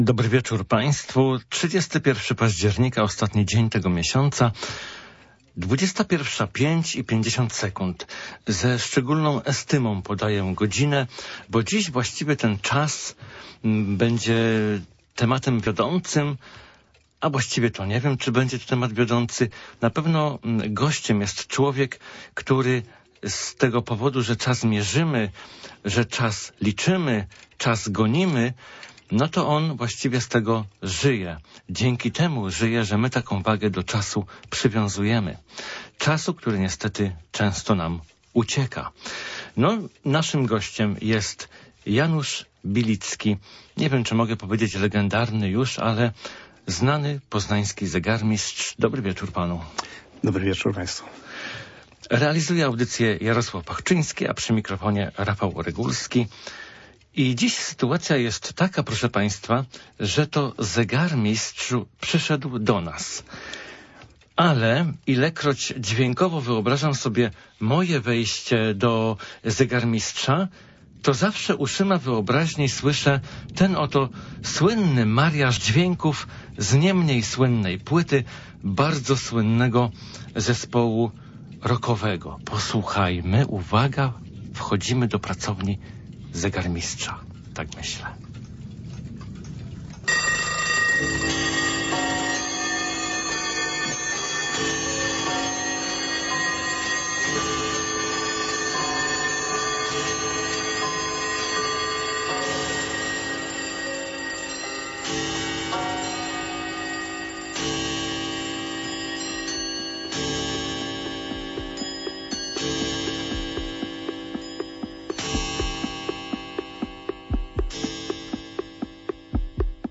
Wsłuchujemy się też (i to akurat nam się udało znakomicie) w zegary: ich mowę, tykanie, gongi i dzwonki. Ale przede wszystkim udowadniamy, że zegarmistrzostwo jest wielką sztuką i przejawem głębokiego znawstwa nauk ścisłych. Polecamy Państwu rozmowę z człowiekiem, który miał do czynienia z najbardziej unikalnymi zegarami na świecie.